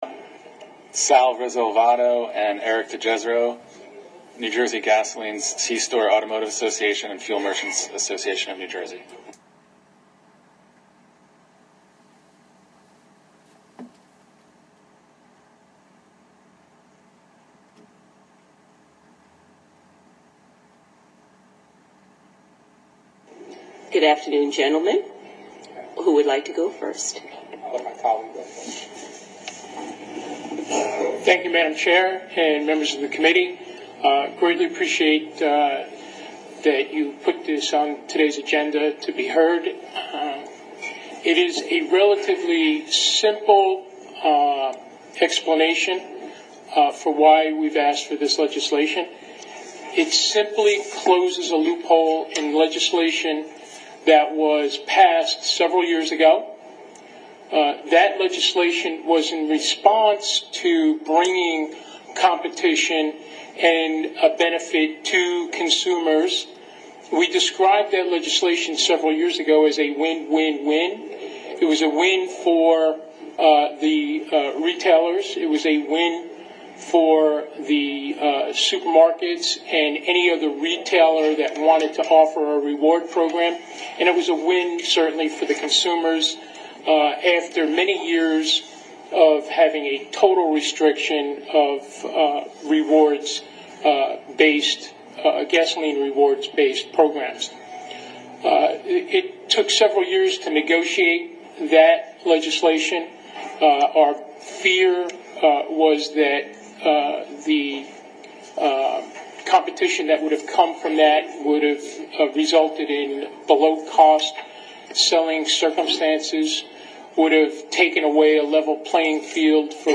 This week, NJGCA and our friends at the Fuel Merchants Association of New Jersey were back in Trenton to testify in support of this legislation in front of the Senate Commerce Committee. The bill was voted out of Committee, and we are now asking that the bill receive a vote in the entire Senate and entire Assembly.